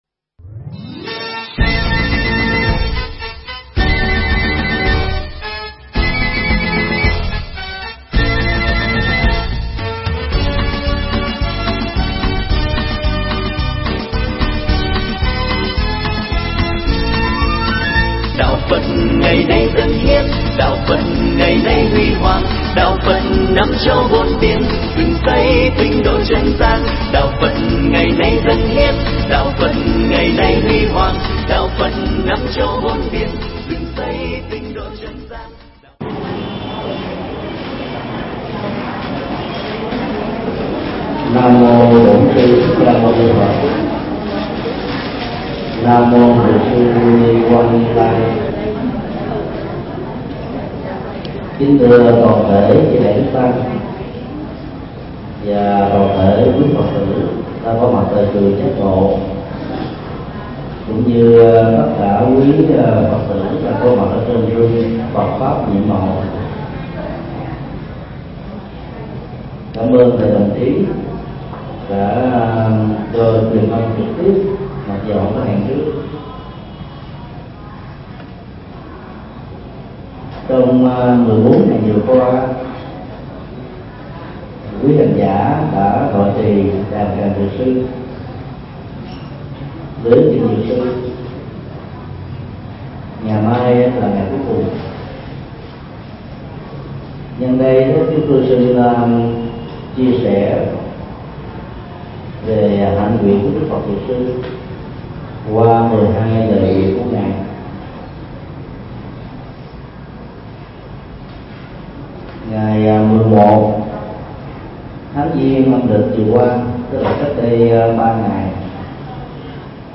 Nghe mp3 pháp thoại Hạnh nguyện đức Phật Dược Sư do thầy Thích Nhật Từ giảng tại Chùa Giác Ngộ, ngày 20 tháng 02 năm 2008.